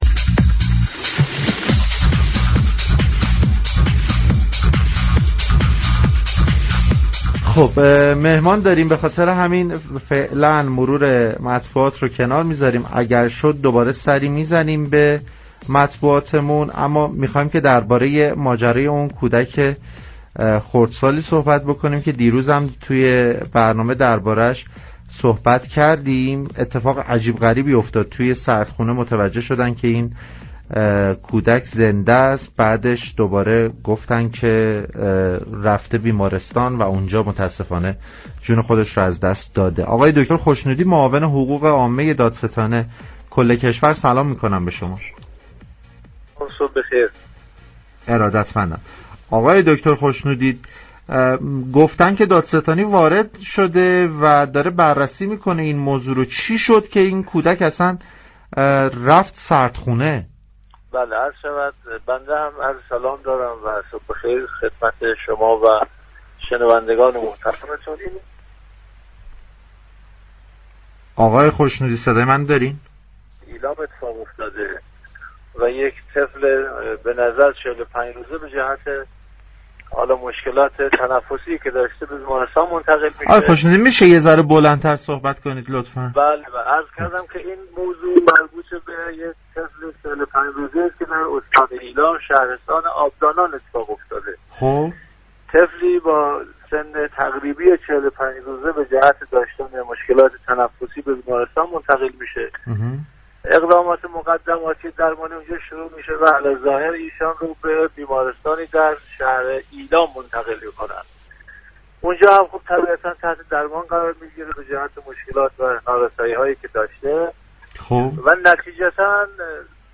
به گزارش پایگاه اطلاع رسانی رادیو تهران، رضا خشنودی در گفتگو با برنامه پارك شهر رادیو تهران درباره فوت نوزاد دوماهه آبدانانی و پرونده شبهه برانگیز وی گفت: این حادثه در آبدانان استان ایلام اتفاق افتاده است.